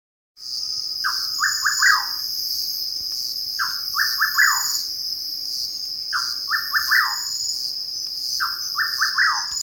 Rufous Nightjar (Antrostomus rufus)
Life Stage: Adult
Detailed location: A orillas del río Tapenaga
Condition: Wild
Certainty: Recorded vocal
Atajacaminos-colorado-Mp3.mp3